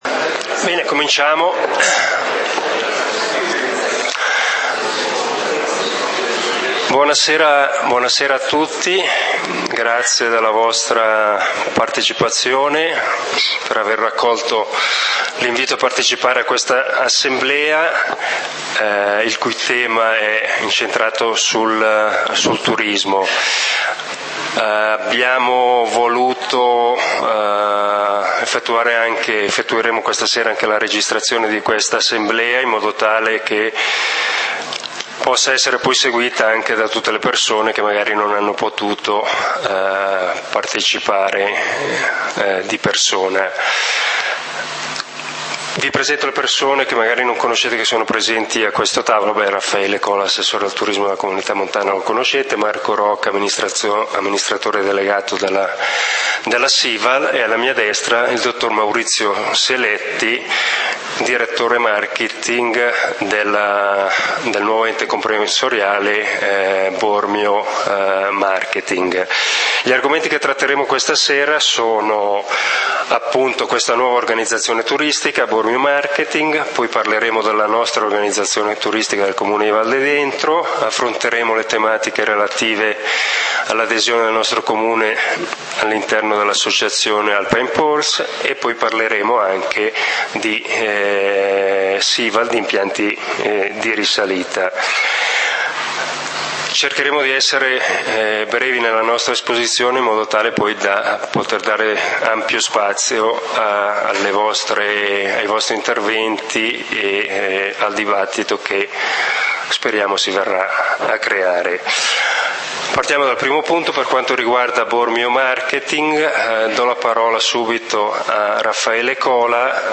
Turismo: informazione, confronto ed approfondimento Assembrela pubblica del comunale di Valdidentro del 14 Ottobre 2013
Introduzione: Adriano Martinelli, assessore turismo Comune di Valdidentro - Raffaele Cola, assessore turismo della C.M. Alta Valtellina